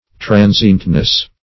Tran"sient*ness, n.